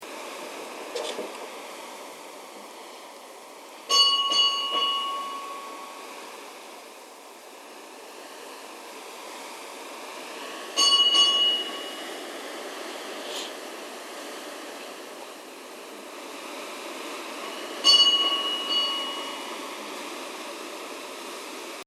Í turni kirkjunnar eru tvær klukkur, önnur meðalstór en hin lítil. Stærri klukkan er nokkuð skrautleg og með áletruninni STØBT AF P PETERSEN KIOBENHAVN ANNO 1804 en minni klukkan er ómerkt.
Litla klukkan Staðarkirkja á Reykjanesi.
stadarkirkja_reyk_litla.mp3